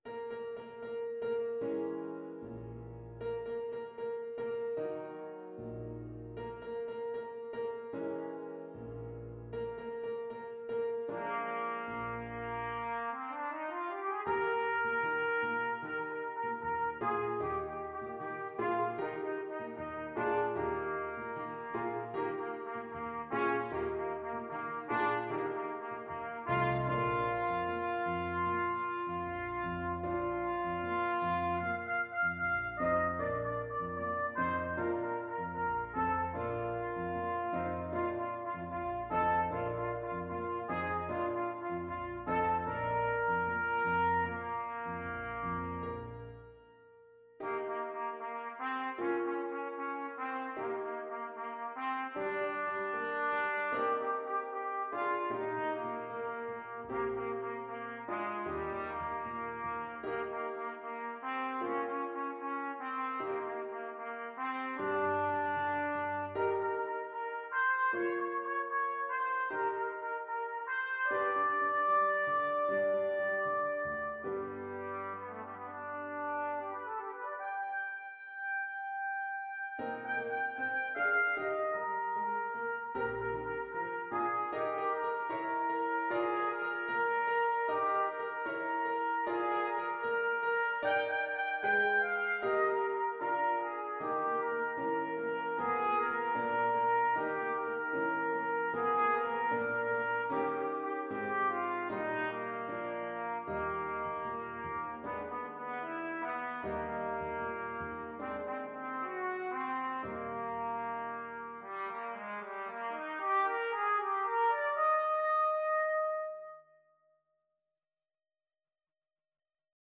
Classical Saint-Saëns, Camille Havanaise, Op.83 Trumpet version
2/4 (View more 2/4 Music)
Eb major (Sounding Pitch) F major (Trumpet in Bb) (View more Eb major Music for Trumpet )
=76 Allegretto lusinghiero =104
Classical (View more Classical Trumpet Music)